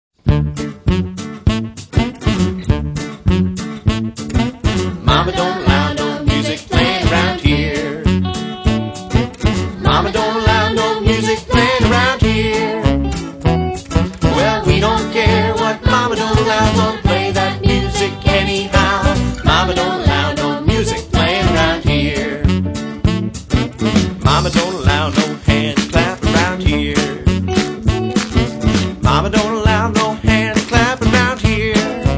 (Traditional American folk song; Public Domain)